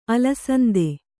♪ alasande